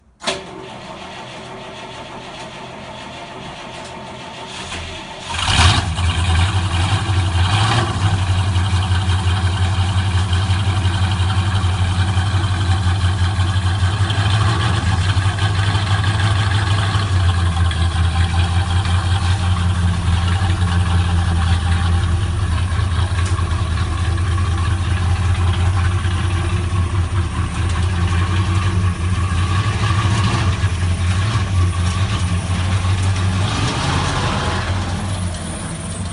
3. ГАЗ-24 V8 запуск
gaz24-v8-start.mp3